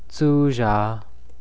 4. Final Obstruent Drop
All obstruents (stops, affricates, and fricatives) are dropped at the ends of words.
Example: /tsuʒa̤k/ --> [
tsuʒa̤] 'tired'